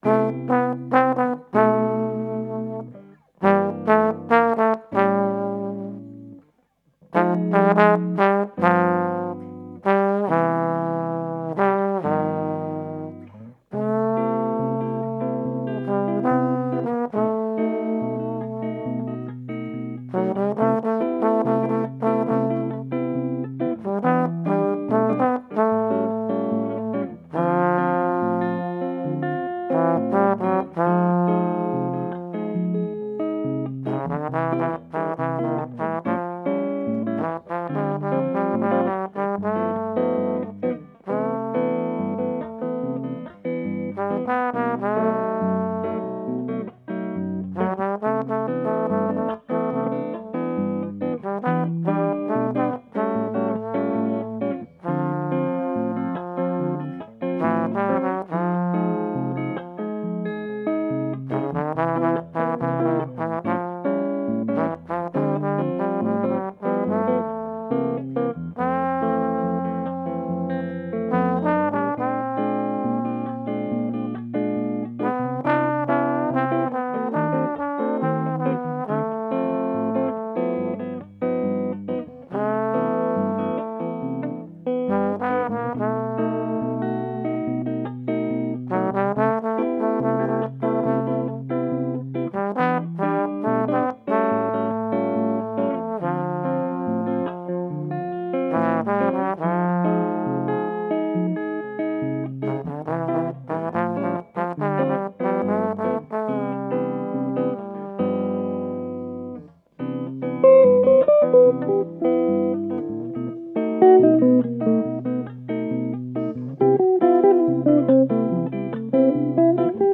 Afinado Duo, est un duo de bossa nova et de musique Brésilienne, vous proposant un concert sobre et festif alliant la chaleur du brésil et la classe du jazz. Composés d'une guitare et d'un trombone à coulisse, pour toutes sortes d’événements (mariage, coc